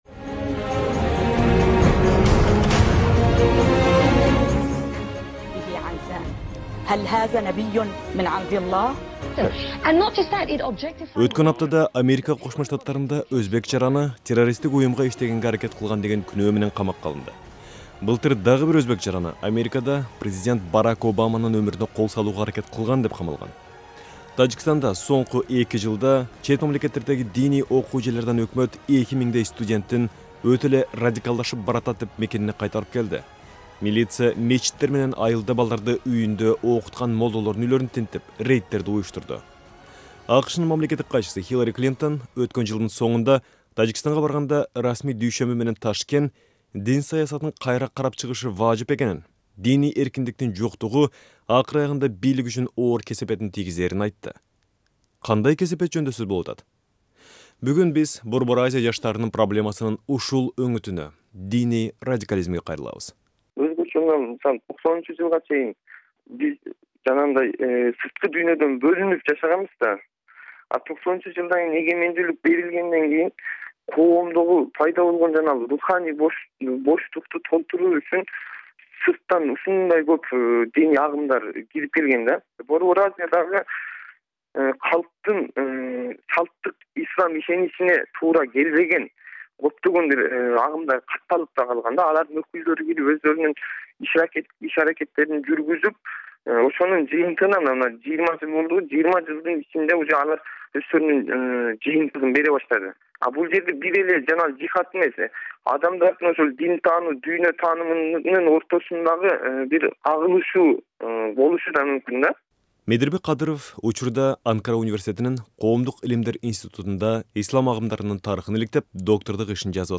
Report on religious radicalism among Central Asian youth.